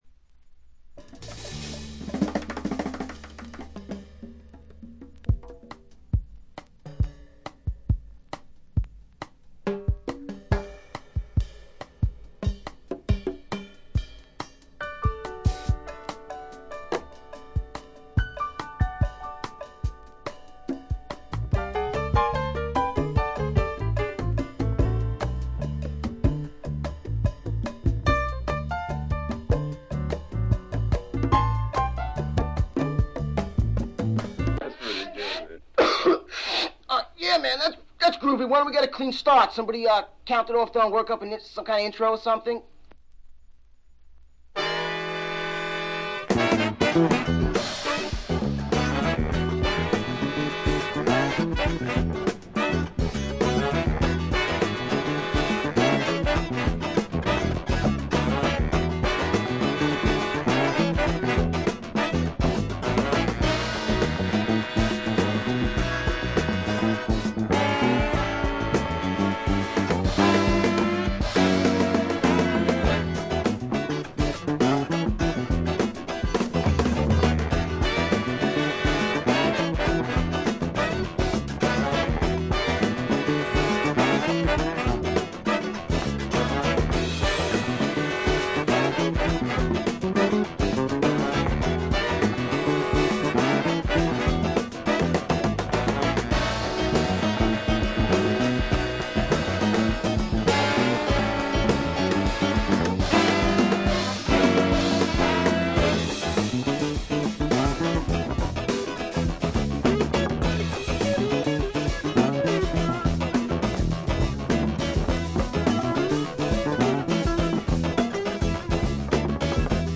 微細なバックグラウンドノイズのみでほとんどノイズ感無し。
よりファンキーに、よりダーティーにきめていて文句無し！
試聴曲は現品からの取り込み音源です。